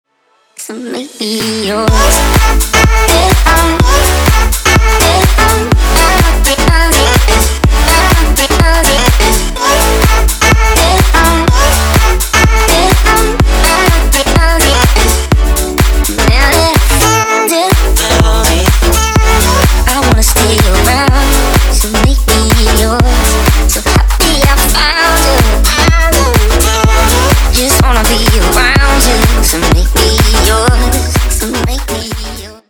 • Качество: 320, Stereo
громкие
dance
future house
club
забавный голос